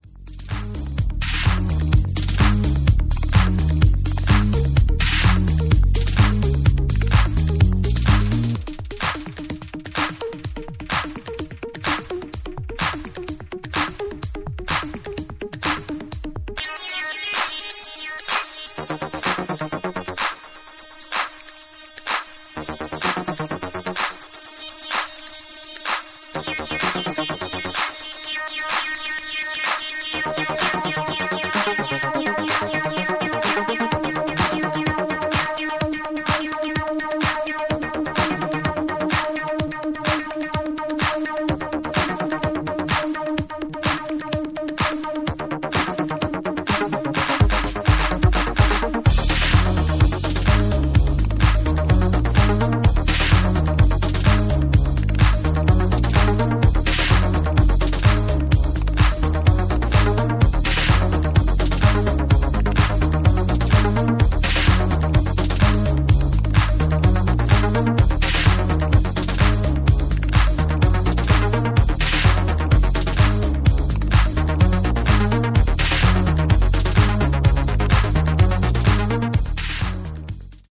humming with vital percussive textures
Techno